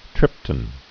(trĭptn)